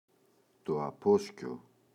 απόσκιο, το [a’posco] – ΔΠΗ